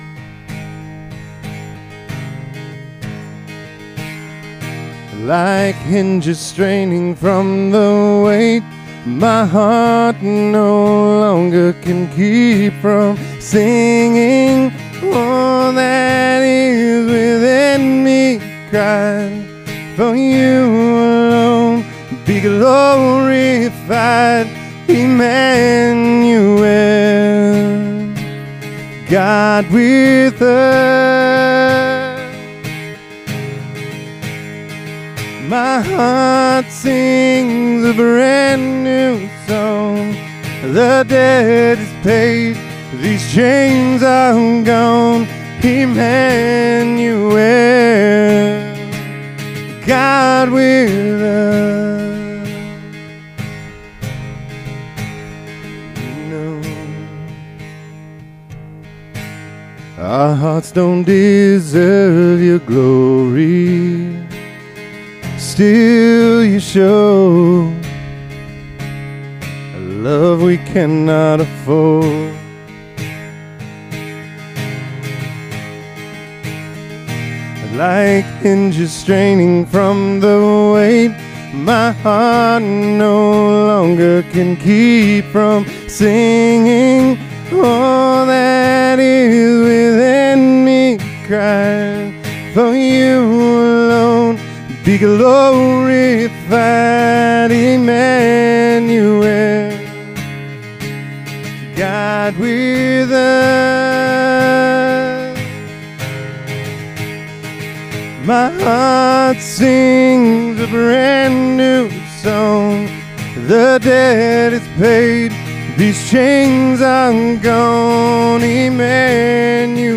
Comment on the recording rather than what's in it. PALM SUNDAY 2026 (BEACH) Current Sermon WHO IS THE KING?